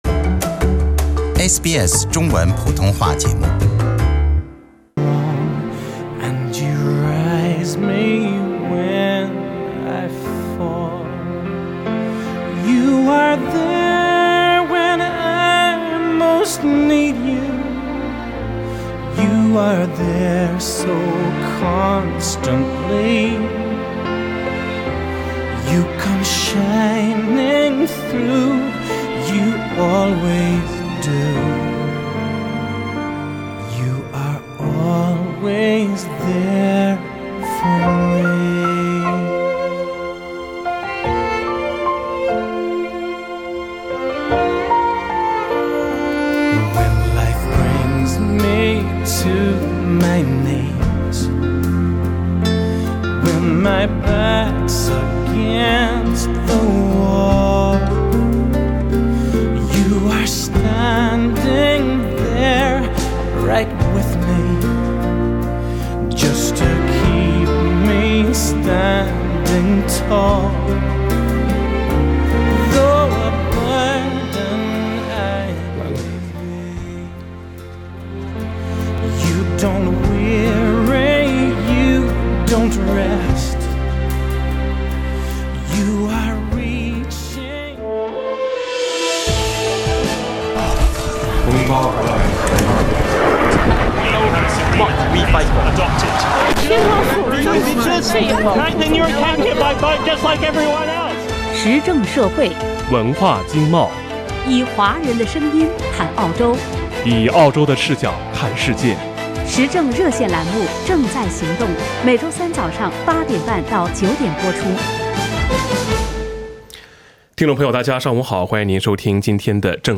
以上为听众发言节选，不代表本台立场。）